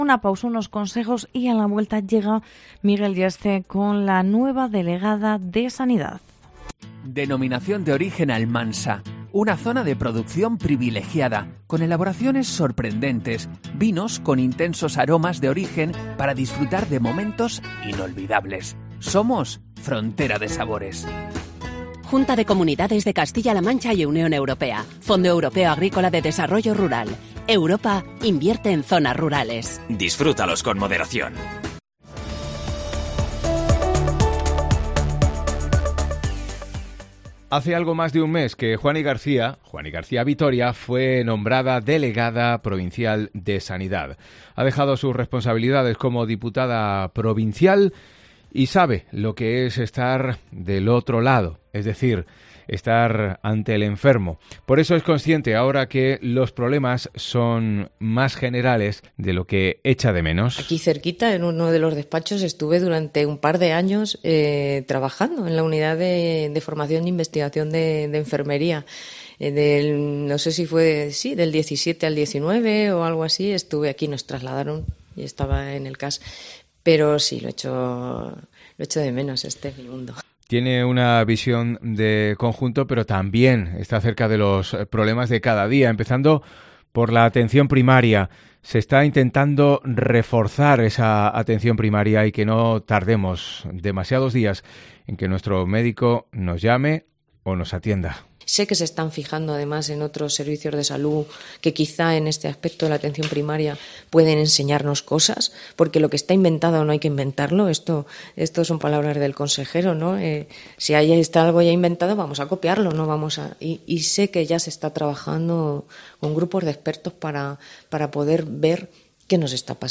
ENTREVISTA COPE
Entrevista con Juani García, nueva delegada provincial de Sanidad